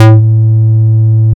DS_Bass05_A2.wav